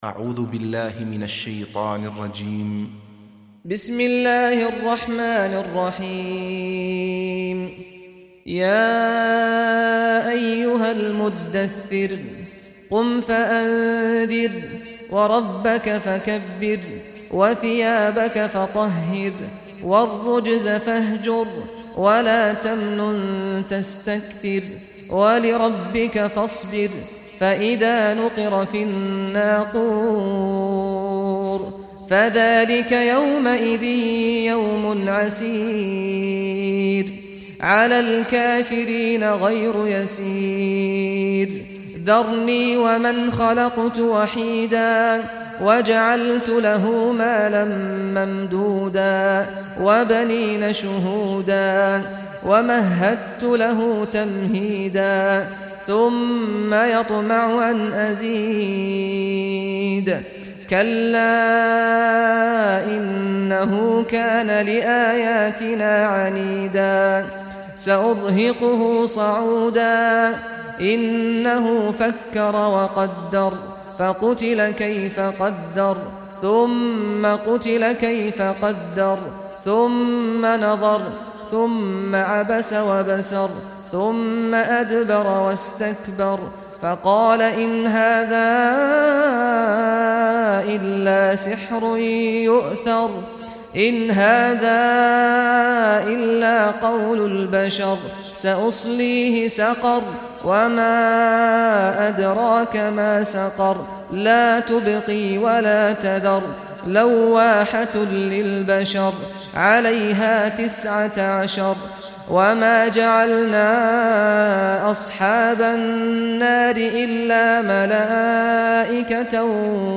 تحميل سورة المدثر mp3 بصوت سعد الغامدي برواية حفص عن عاصم, تحميل استماع القرآن الكريم على الجوال mp3 كاملا بروابط مباشرة وسريعة